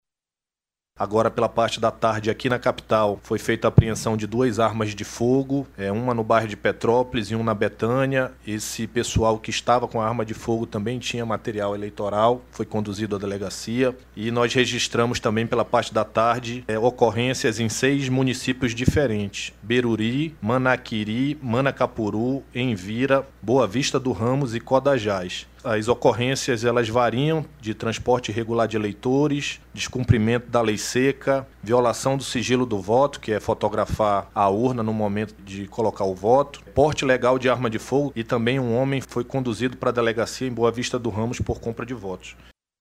De acordo com os números divulgados, durante a coletiva de imprensa, nas primeiras cinco horas do pleito, 20 urnas apresentaram problemas, sendo que 11 precisaram ser substituídas.
O comandante-geral da Polícia Militar do Amazonas, coronel Thiago Balbi, responsável pela Operação Eleições, também apresentou dados referentes aos casos registrados no período da tarde.